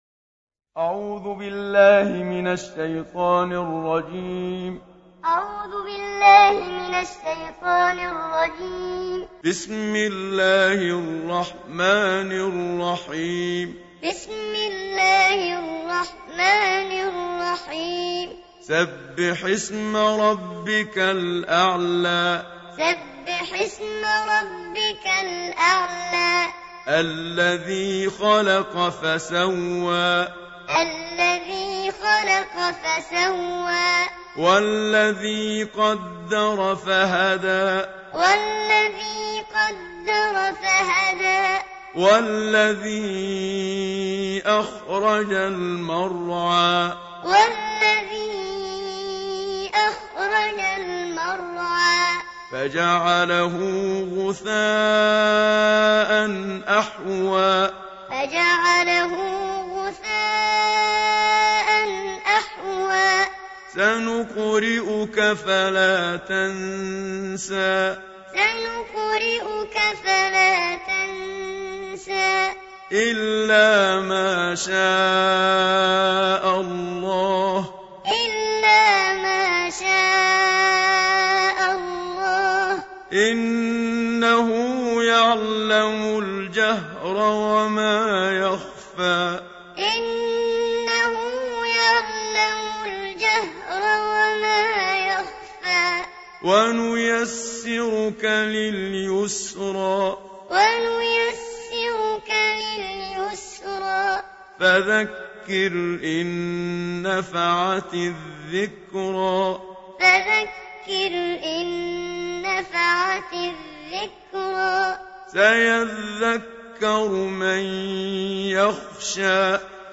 Surah Sequence تتابع السورة Download Surah حمّل السورة Reciting Muallamah Tutorial Audio for 87. Surah Al-A'l� سورة الأعلى N.B *Surah Includes Al-Basmalah Reciters Sequents تتابع التلاوات Reciters Repeats تكرار التلاوات